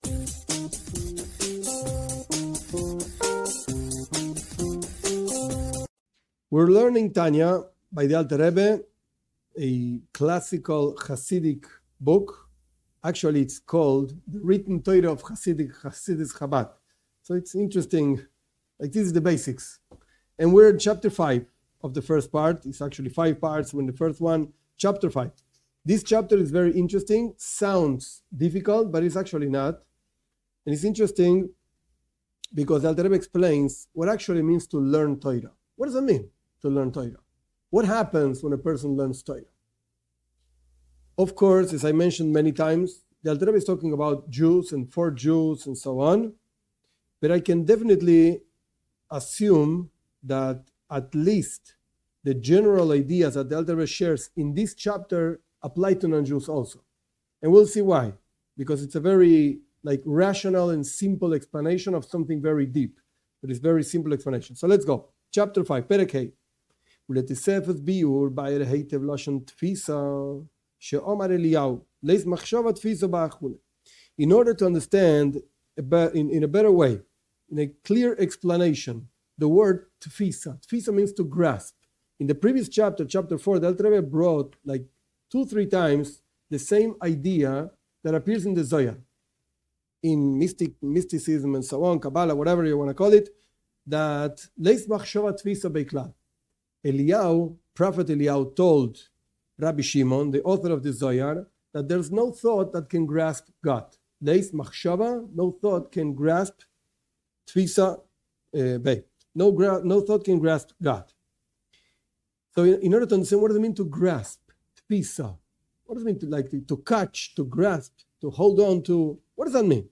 This class teaches Tanya, Book of Beinonim (intermediate people). This is the most basic book of Chabad Chassidism by the Alter Rebbe, Rabi Shneur Zalman of Liadi, of blessed memory.